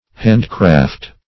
Meaning of hand-craft. hand-craft synonyms, pronunciation, spelling and more from Free Dictionary.
[1913 Webster] handcraft The Collaborative International Dictionary of English v.0.48: handcraft \hand"craft`\, hand-craft \hand"-craft`\v. t. to make (something) by hand.